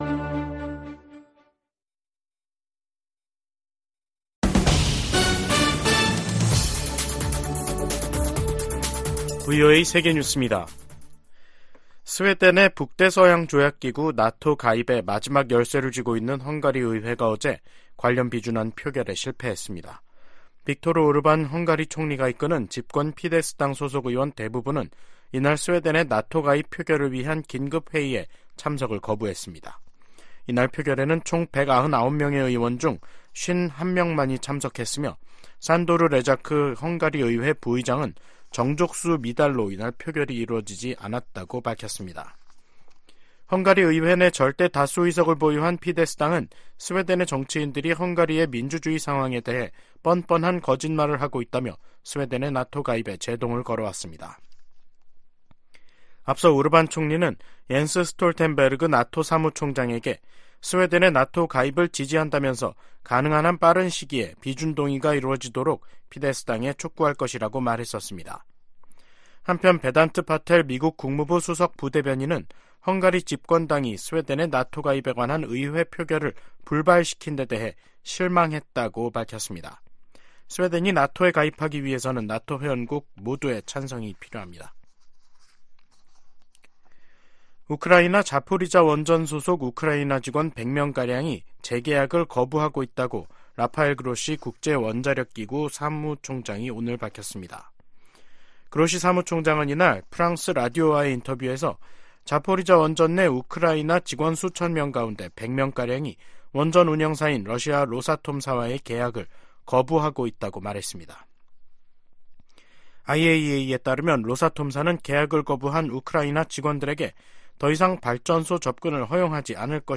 VOA 한국어 간판 뉴스 프로그램 '뉴스 투데이', 2024년 2월 6일 2부 방송입니다. 북한 핵 정책을 비판한 윤석열 한국 대통령에 대해 러시아 외무부 대변인이 비판 논평을 내면서 갈등이 악화될 조짐을 보이고 있습니다. 미국 정부는 북한의 미사일 경보 정보를 한국· 일본과 계속 공유할 것이라고 밝혔습니다. 오는 11월 도널드 트럼프 전 대통령이 당선되면 임기 초 북한과 협상할 가능성이 있다고 존 볼튼 전 국가안보보좌관이 VOA 인터뷰에서 전망했습니다.